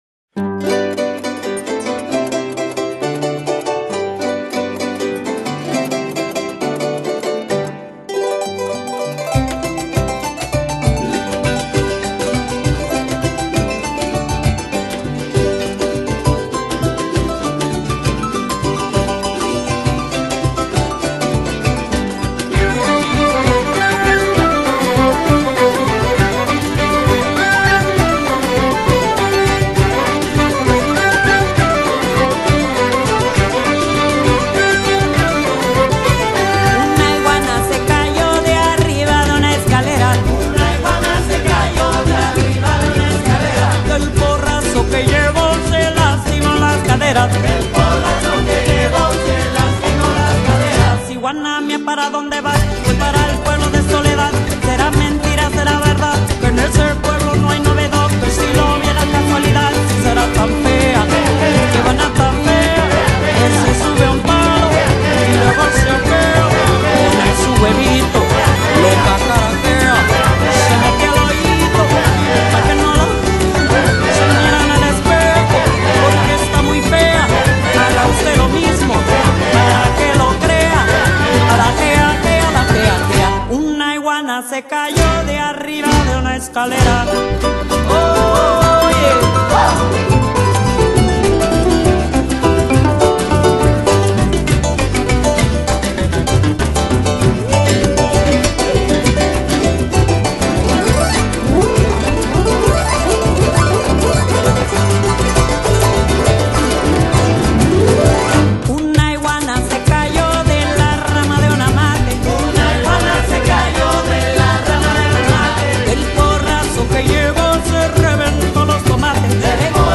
【凱爾特風笛及吟唱】
Genre: Irish Country, Celtic Folk
融合了愛爾蘭和墨西哥的傳統音樂元素，
凱爾特風笛的如泣如訴，男女吟唱的歌謠魅力動人，